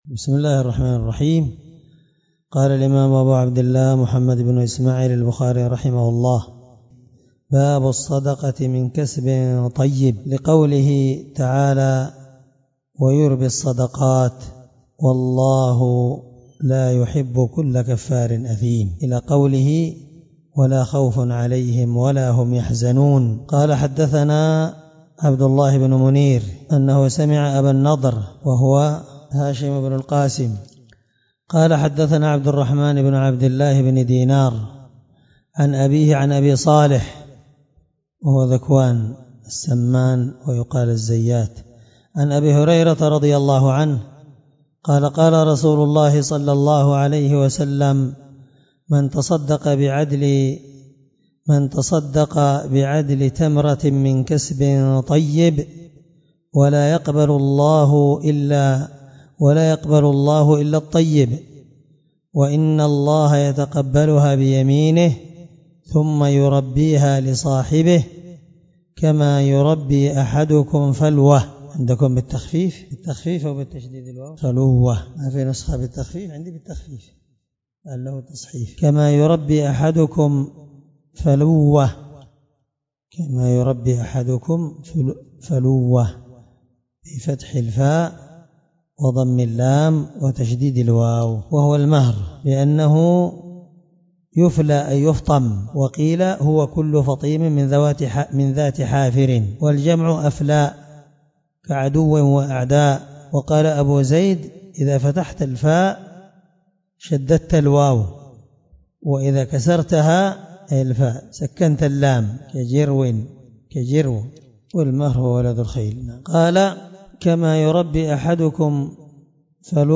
الدرس 9من شرح كتاب الزكاة حديث رقم(1410 )من صحيح البخاري